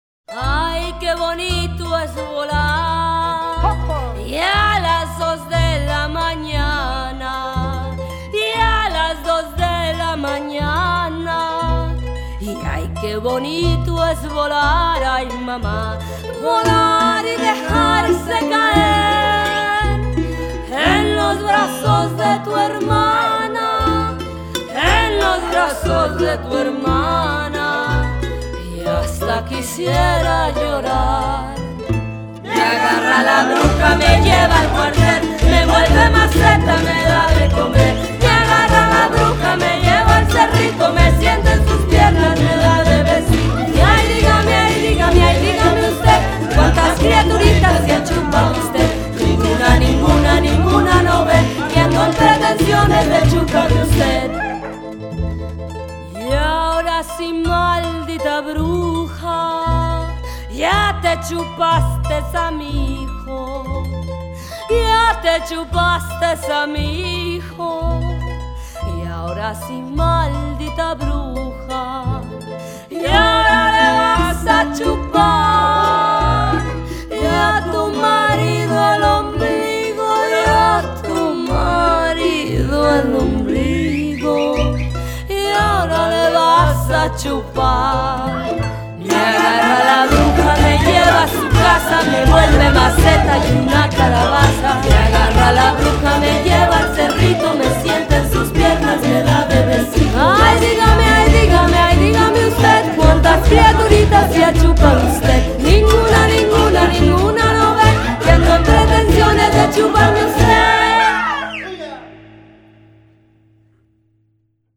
lakodalmas hangulatú danolászásba kezdett egy csehóban